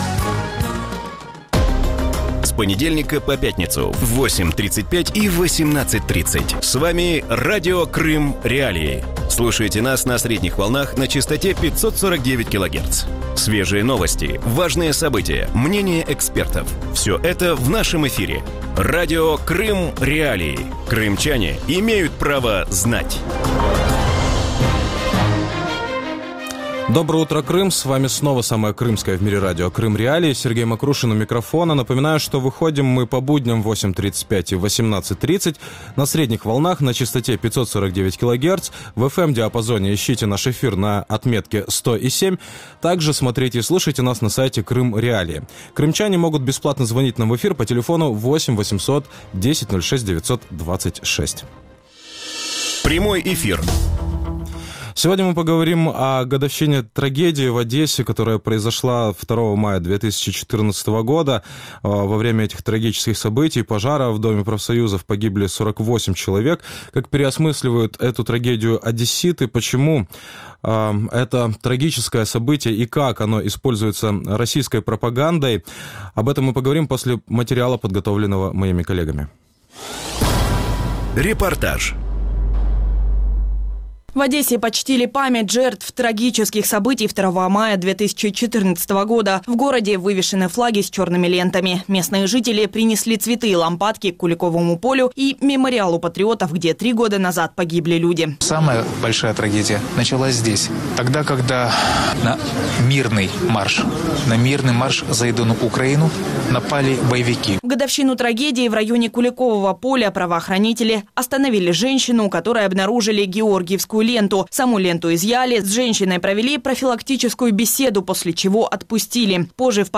Утром в эфире Радио Крым.Реалии говорят о годовщине трагедии в Одессе 2 мая 2014 года. Как проходит расследование дела о гибели 42 человек во время пожара в Доме профсоюзов?